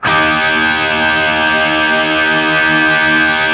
TRIAD D# L-L.wav